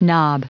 Prononciation du mot knob en anglais (fichier audio)
Prononciation du mot : knob